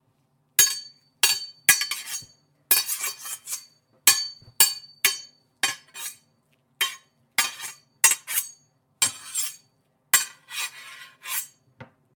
swordfight-2.ogg